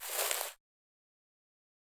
footsteps-single-outdoors-001-00.ogg